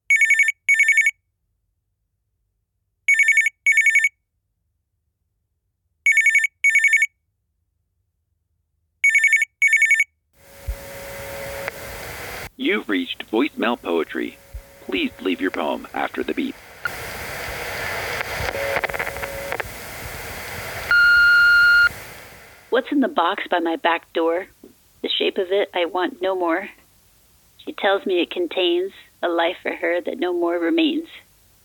Teaser audio for the poetry submissions for the next issue. It's called "voice mail poetry."
I worked with the author for the effect sounds.